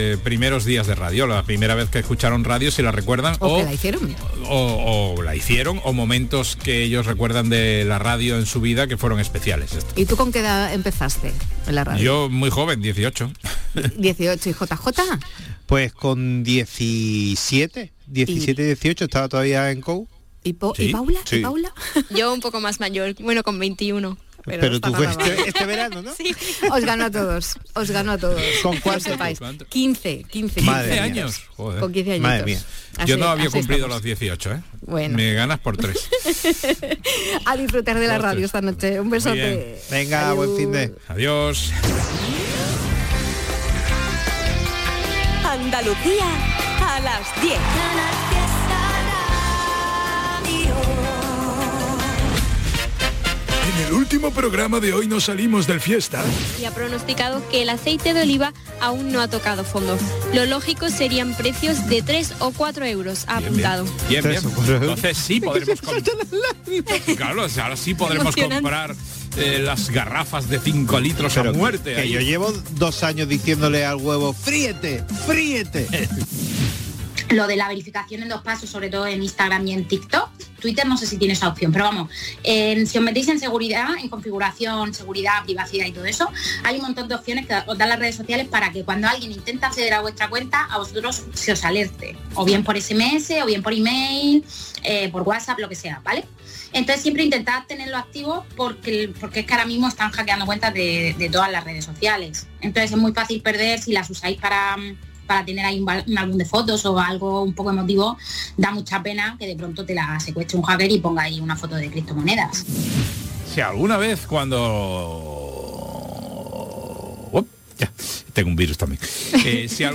Buena música, humor y alguna que otra reflexión para organizarte tus cosas. Canal Fiesta te ofrece un programa nocturno de noticias y curiosidades muy loco. Un late radio show para que te quedes escuchando la radio hasta que te vayas a dormir.